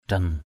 /d̪rʌn/ 1.